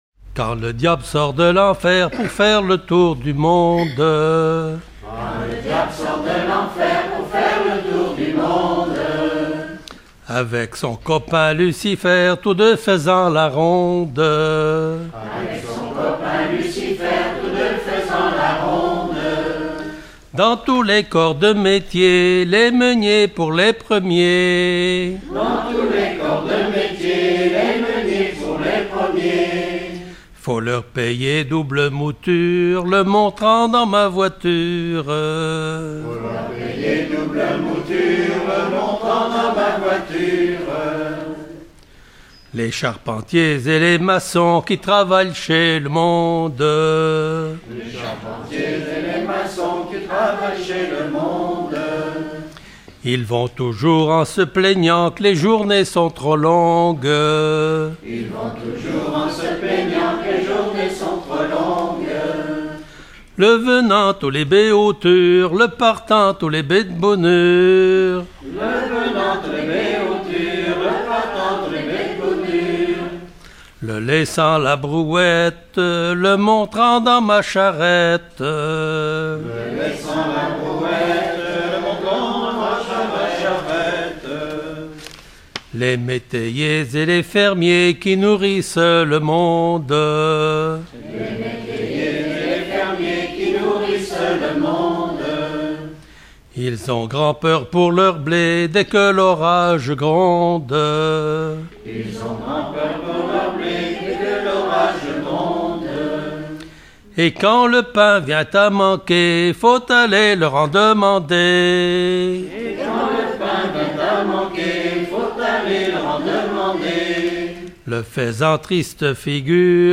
Saint-Christophe-du-Ligneron
chanteur(s), chant, chanson, chansonnette
Genre énumérative